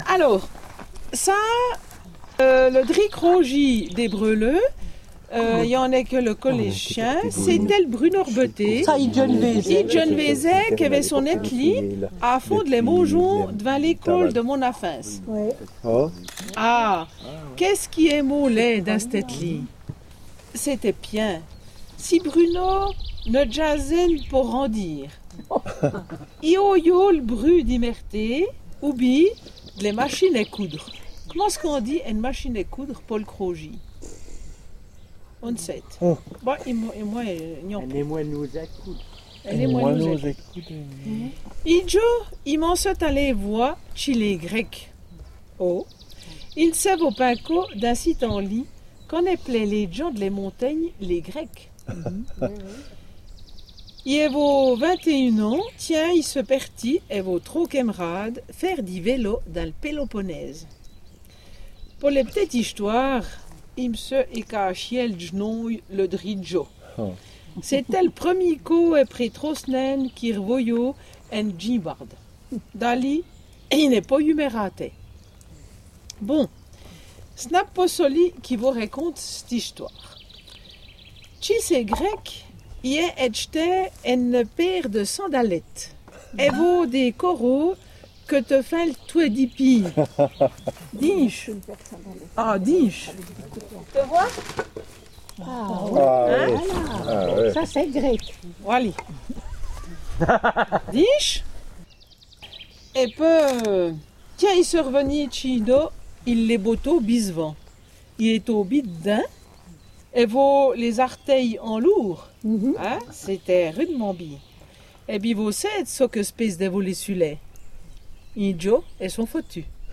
Journées conviviales où l’on rit, chante, se restaure dans un chaud climat d’amitié.